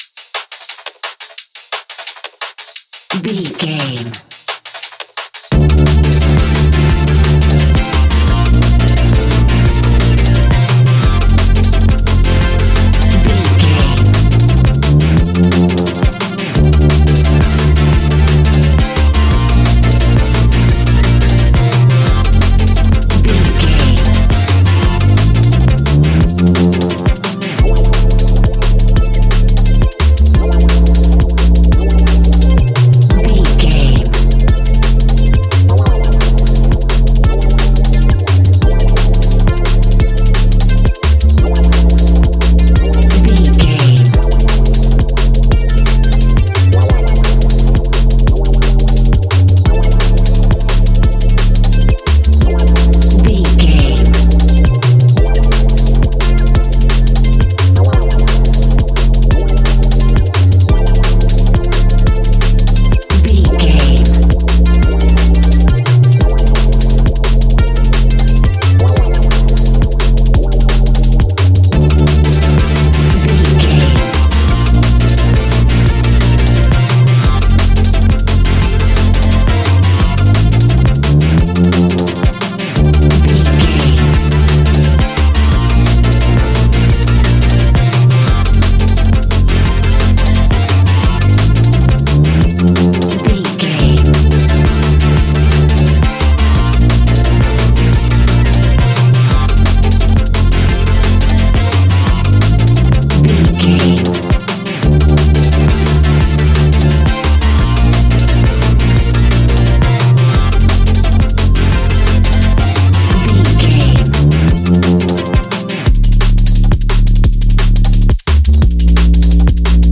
Ionian/Major
A♯
techno
trance
synths
synthwave
instrumentals